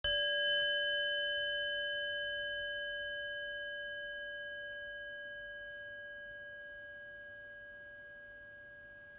tintement-bol-chantant-zenkoan.m4a